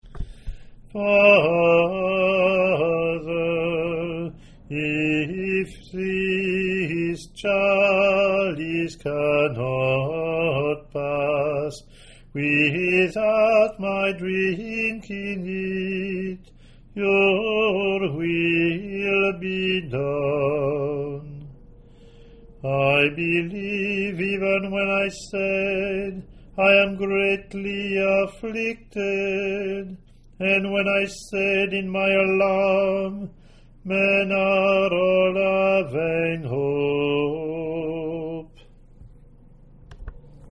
English antiphon – English verse